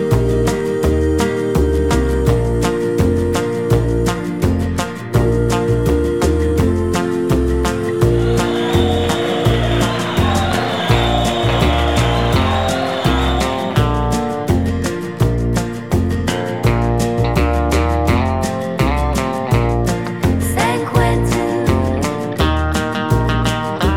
Live With Crowd Country (Male) 2:28 Buy £1.50